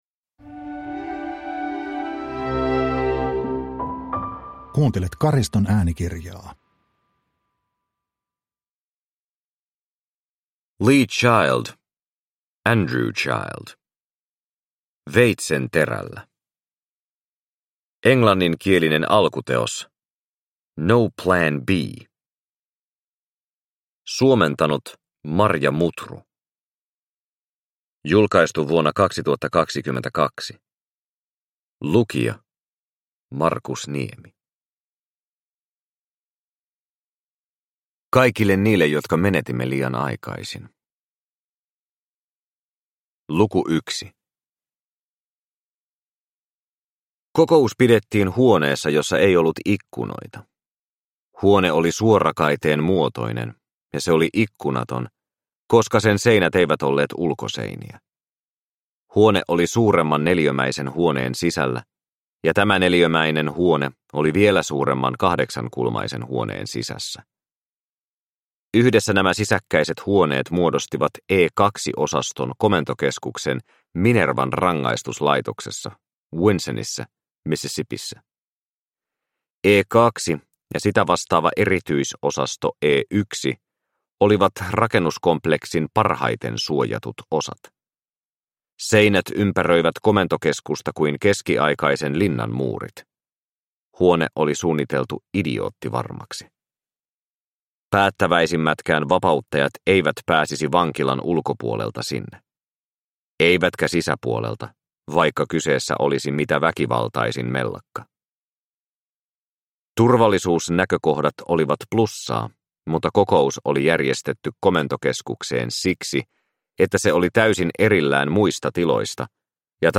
Veitsen terällä – Ljudbok – Laddas ner